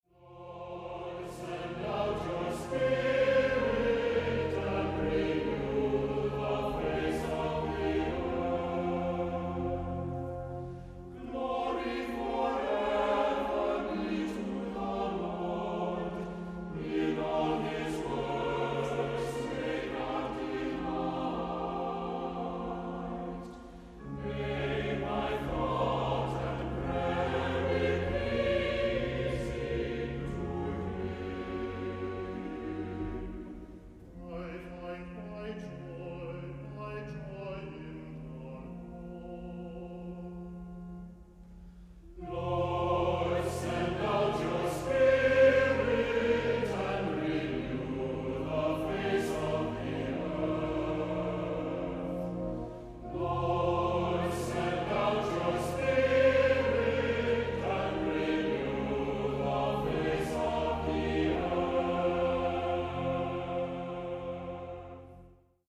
Voicing: Cantor; Assembly